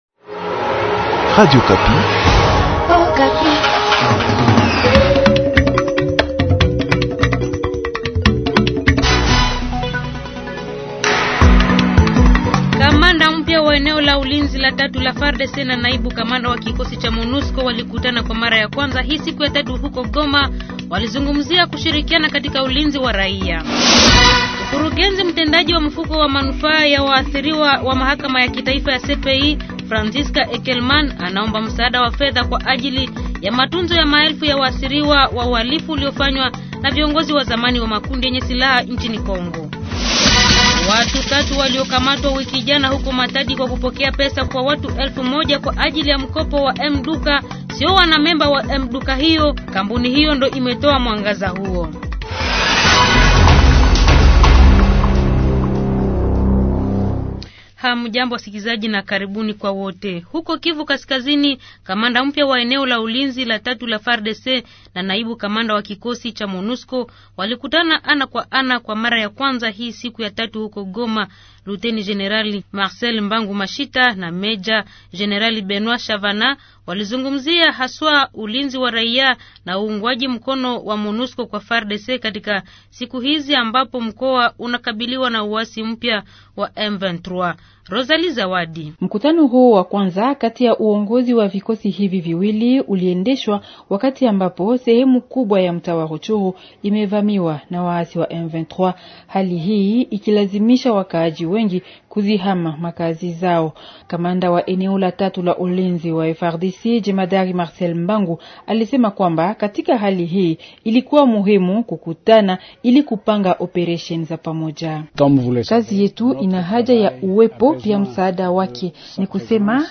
Journal du soir